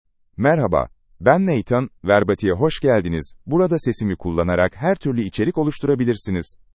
NathanMale Turkish AI voice
Nathan is a male AI voice for Turkish (Turkey).
Voice sample
Listen to Nathan's male Turkish voice.
Male
Nathan delivers clear pronunciation with authentic Turkey Turkish intonation, making your content sound professionally produced.